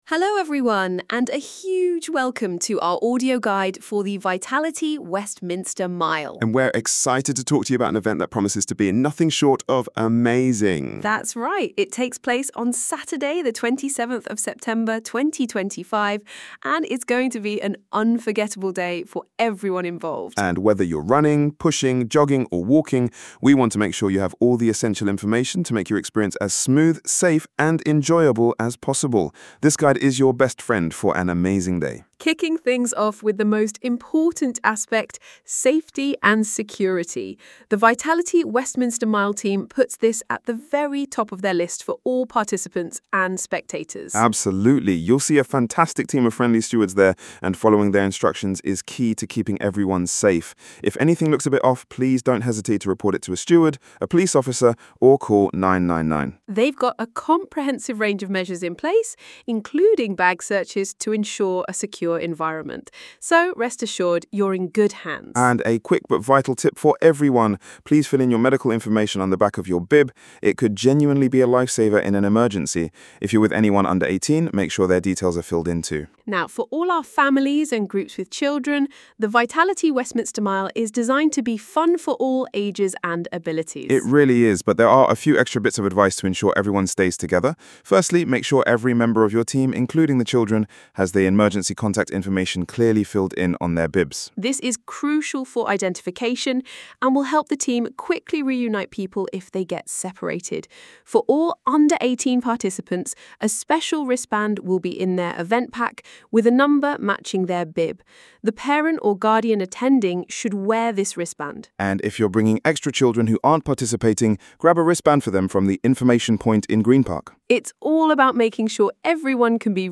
The audio version of the Participant Guide was created using AI-generated audio.